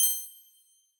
Coins (6).wav